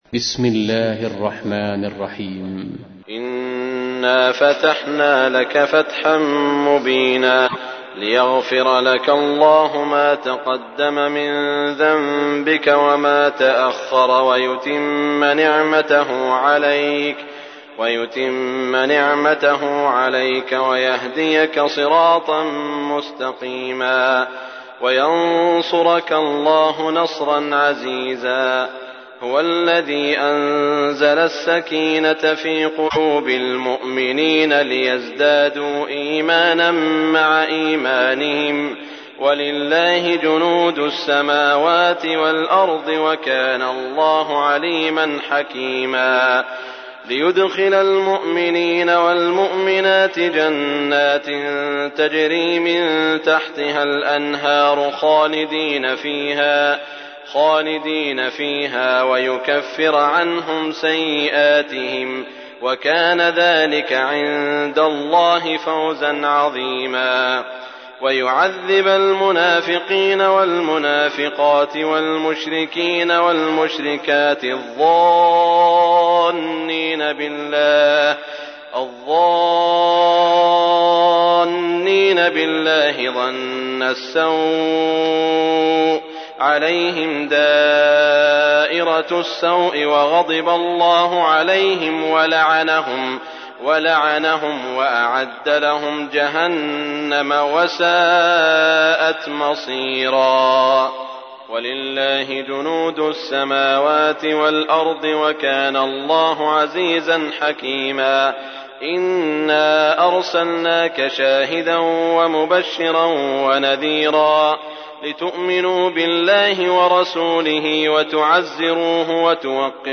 تحميل : 48. سورة الفتح / القارئ سعود الشريم / القرآن الكريم / موقع يا حسين